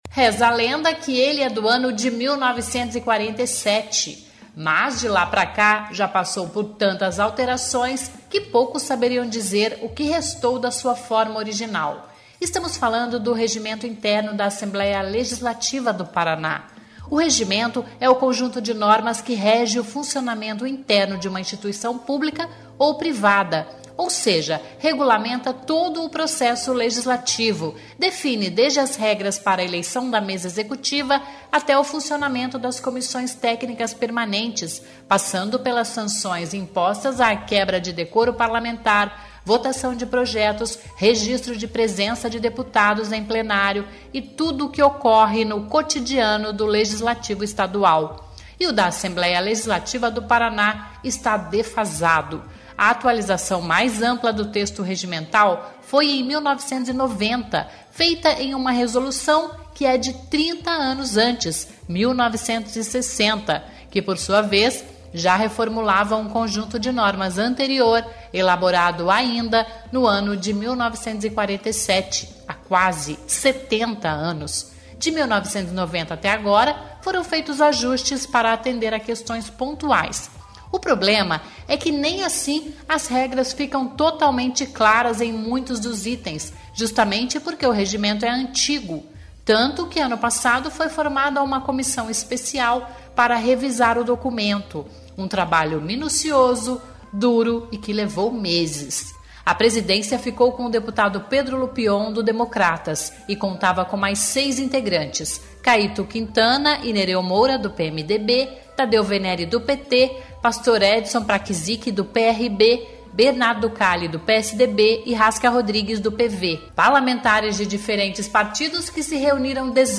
Reportagem Especial: Conheça o Regimento Interno da Assembleia e as mudançasno documento ao longo dos anos